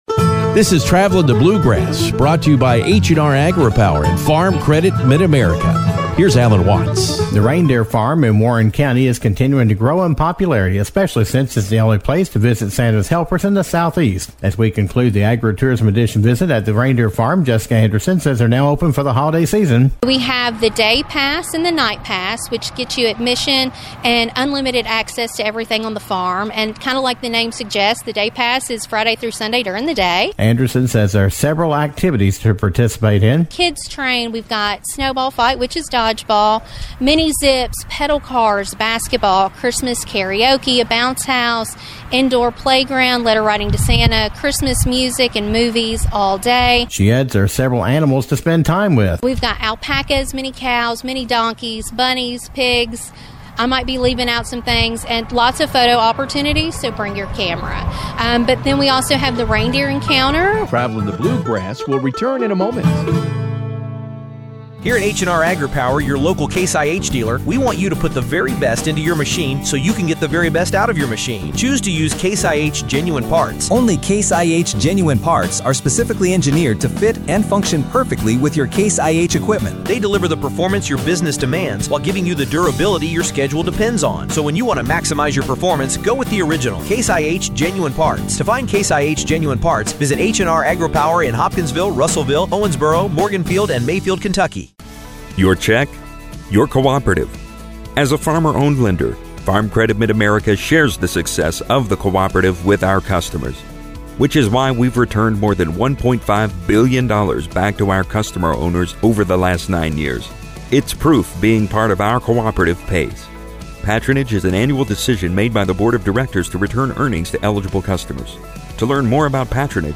Travelin the Bluegrass concludes the Agritourism Edition visit with Kentucky Tourism Arts and Heritage Cabinet at the Reindeer Farm in Bowling Green